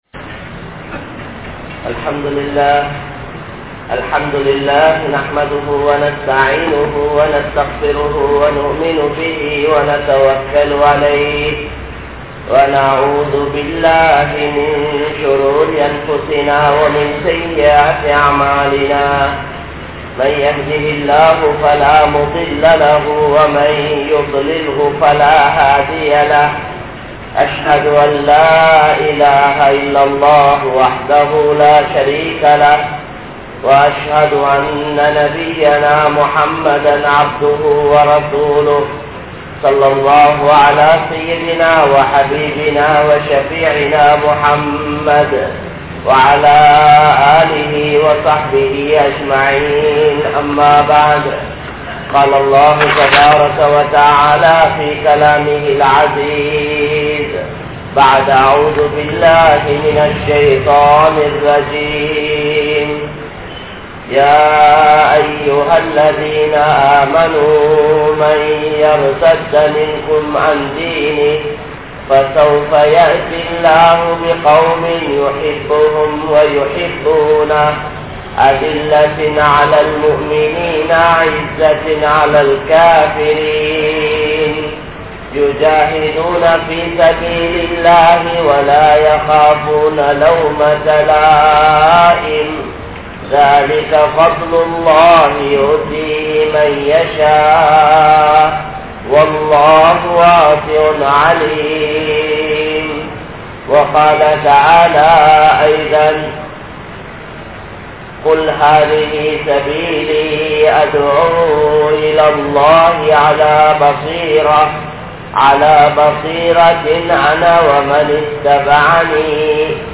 Muslimkale! Natpanpuhaludan Vaalungal (முஸ்லிம்களே! நற்பண்புகளுடன் வாழுங்கள்) | Audio Bayans | All Ceylon Muslim Youth Community | Addalaichenai